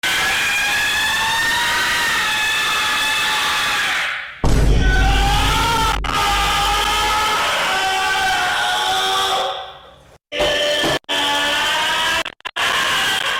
Memes
Funny Loud Scream 2